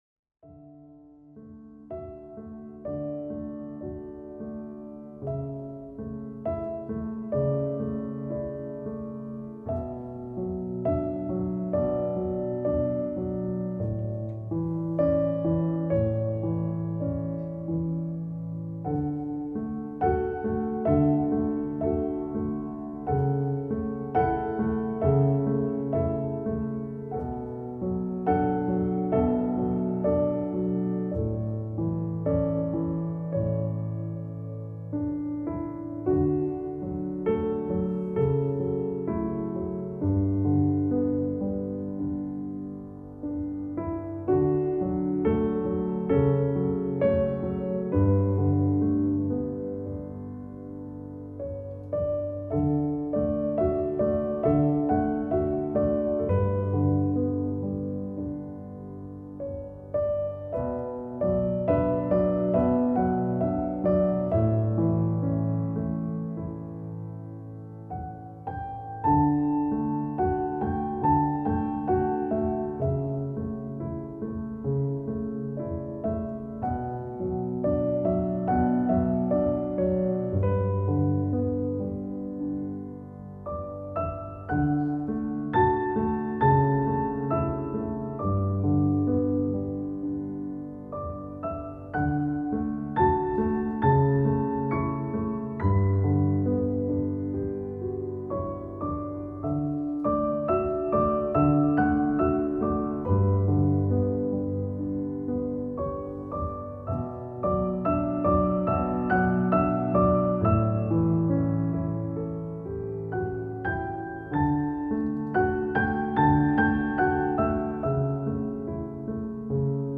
موسیقی آرامش ۳